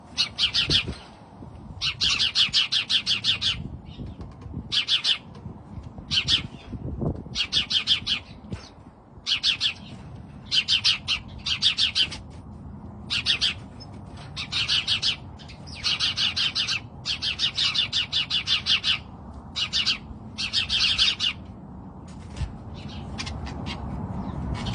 家麻雀叫声